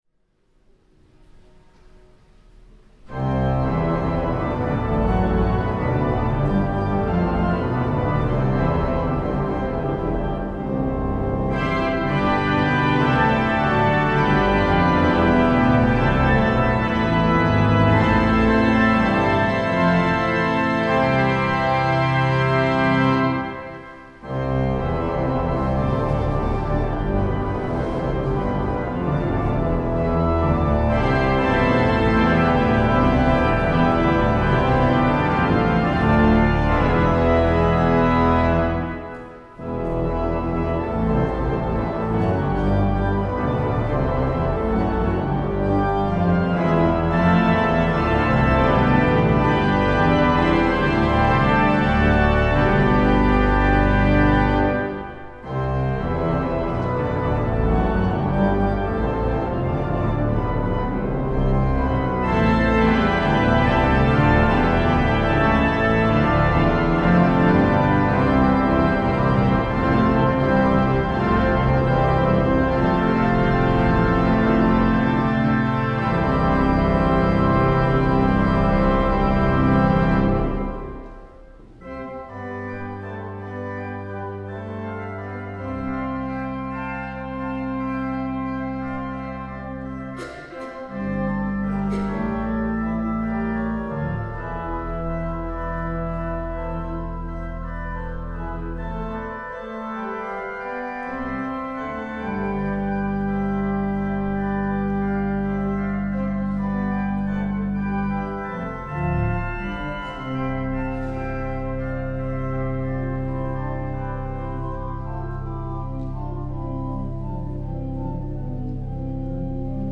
Dezember, 2014, 10:00 Uhr, Martin-Luther-Kirche, Ulm
Singegottesdienst
Orgelnachspiel Paulmichl: Vom Himmel hoch